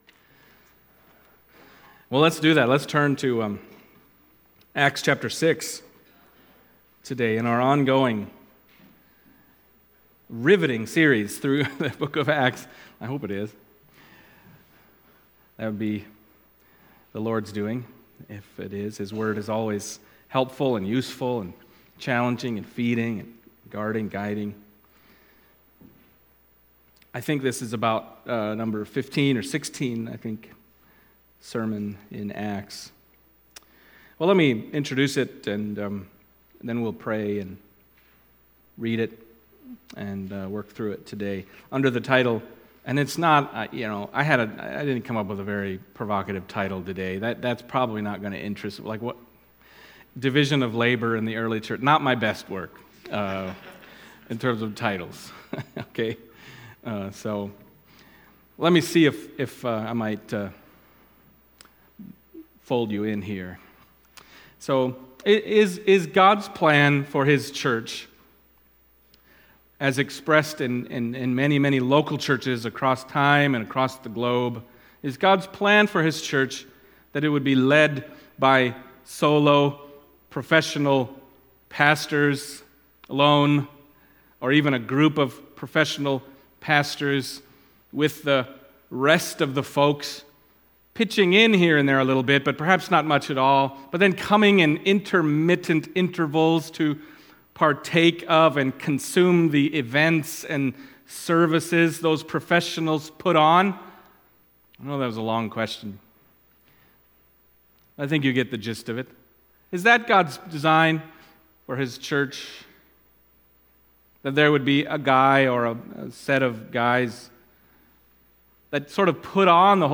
Acts Passage: Acts 6:1-7 Service Type: Sunday Morning Acts 6:1-7 « Rejoice to Suffer for the Name Stephen